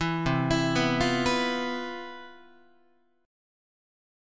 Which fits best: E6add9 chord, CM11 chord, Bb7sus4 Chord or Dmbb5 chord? CM11 chord